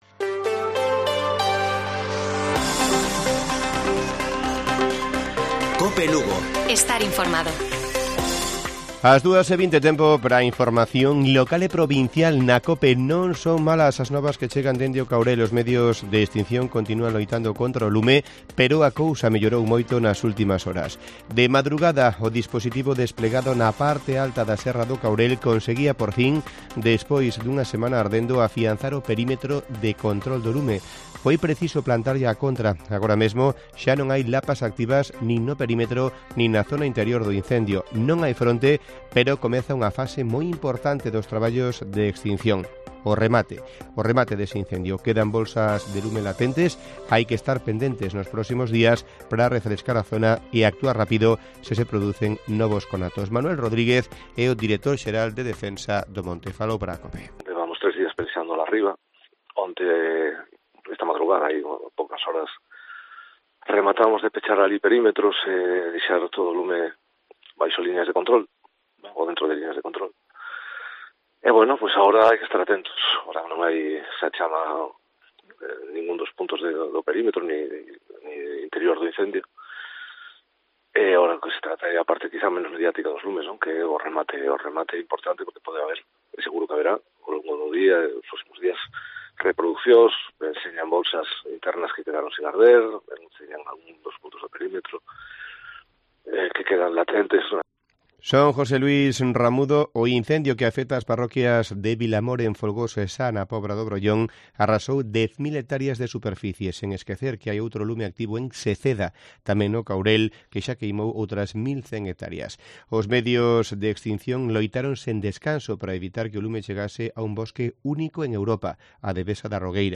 Informativo Mediodía de Cope Lugo. 22 de julio. 14:20 horas